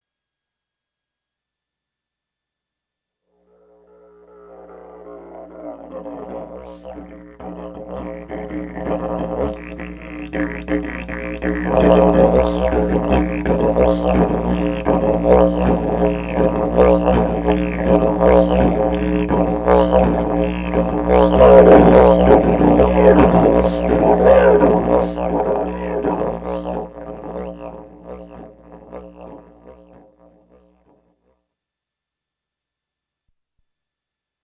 A deep bass didgeridoo.
csharp_didgmp3.mp3